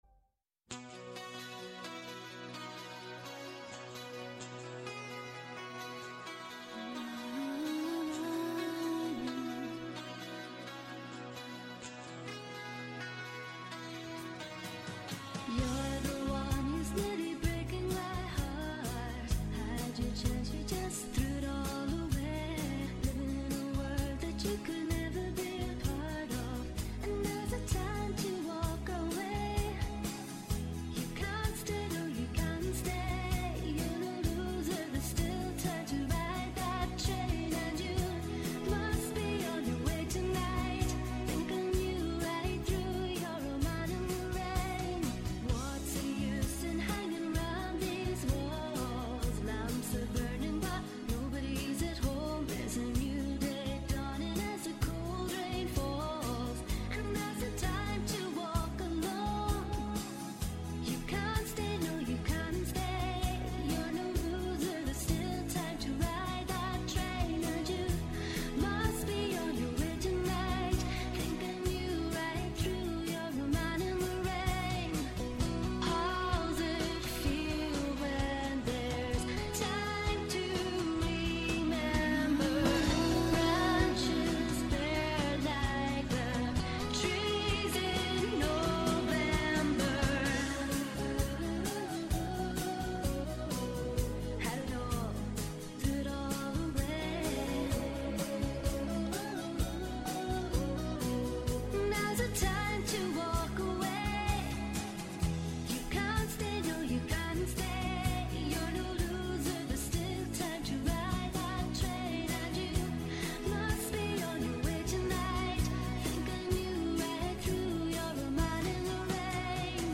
Καλεσμένος απόψε στο στούντιο